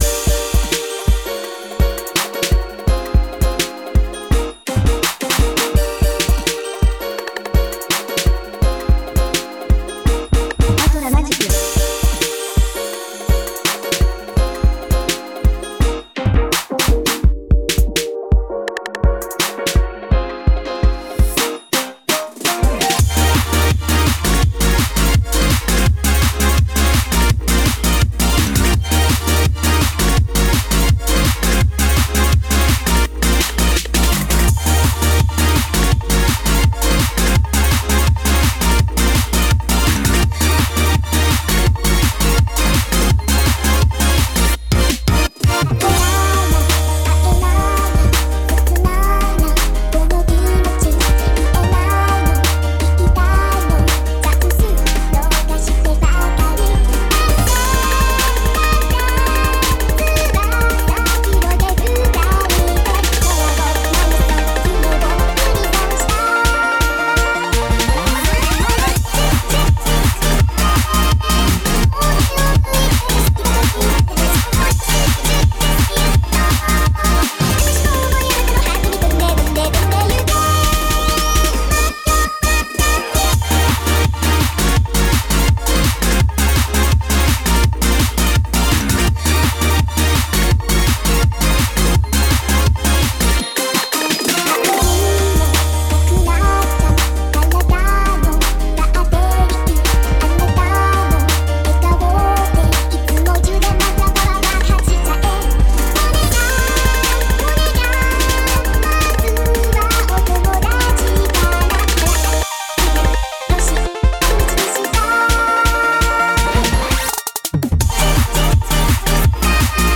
My set was played at 0:35.